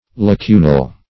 Lacunal \La*cu"nal\ (l[.a]*k[=u]"nal)